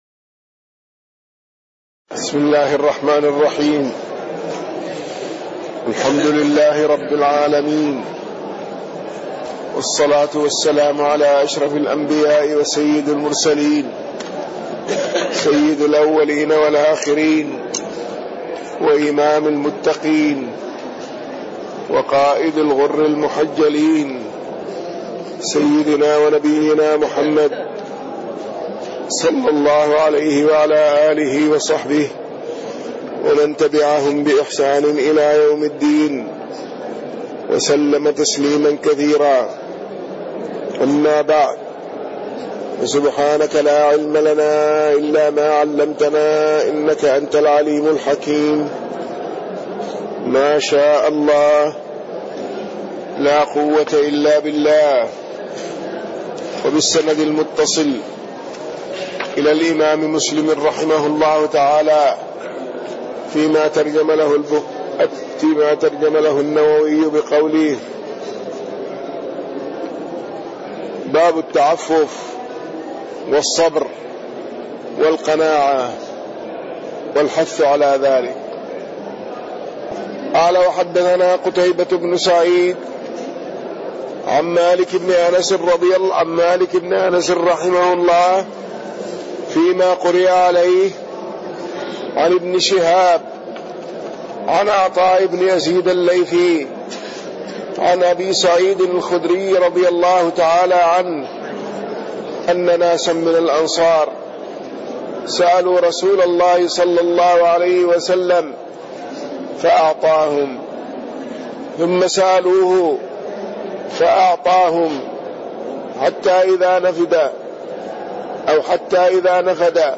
تاريخ النشر ١٦ شوال ١٤٣٢ هـ المكان: المسجد النبوي الشيخ